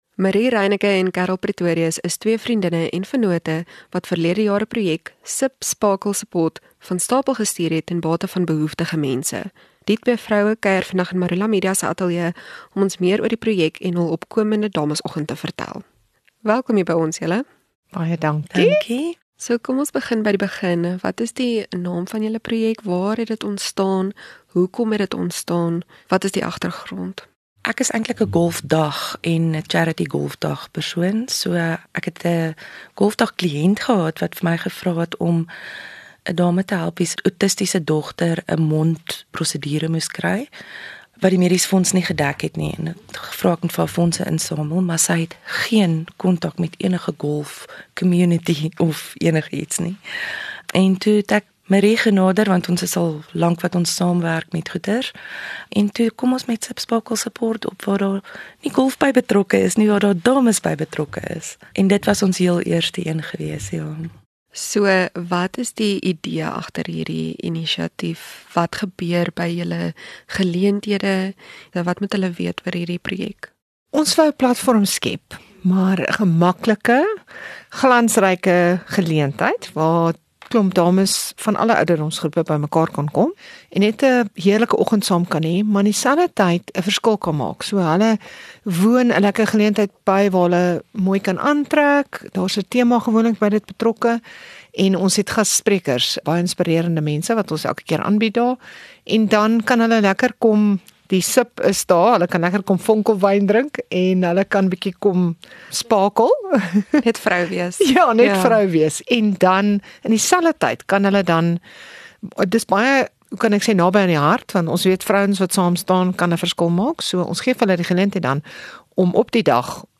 Maroela-onderhoude